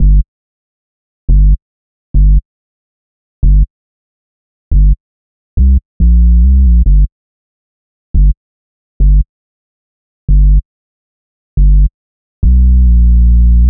Cloud Bass 140 bpm.wav